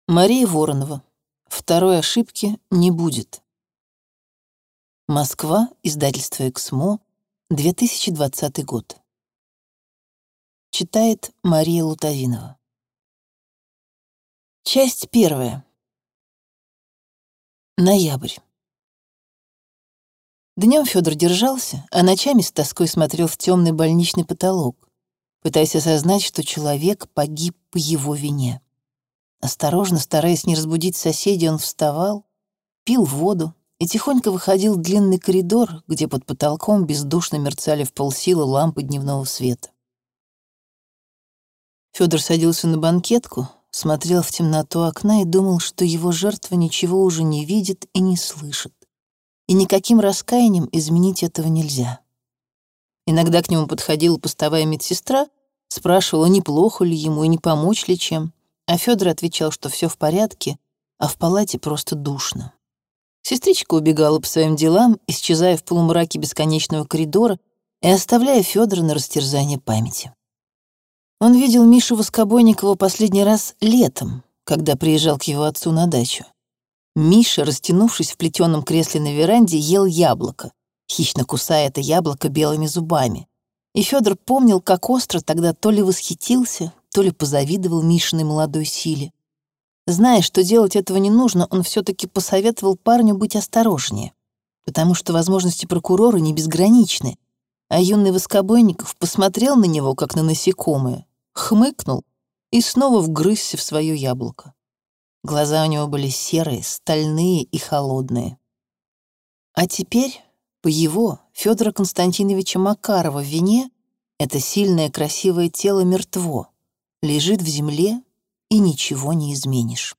Аудиокнига Второй ошибки не будет | Библиотека аудиокниг